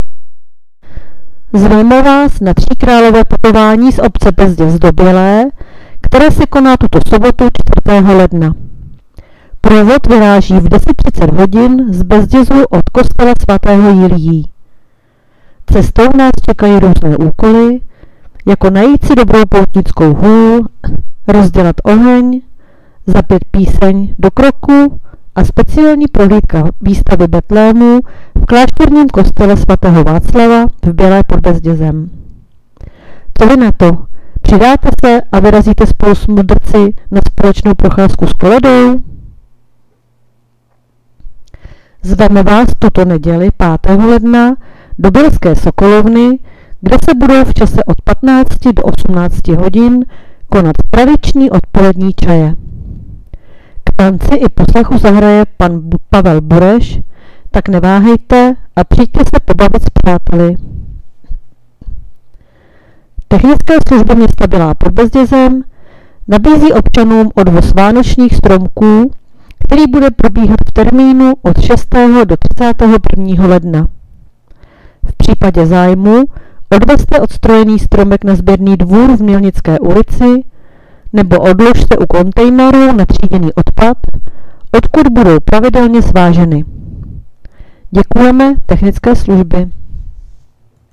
Hlášení městského rozhlasu 3.1.2025